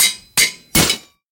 Anvil destroy.ogg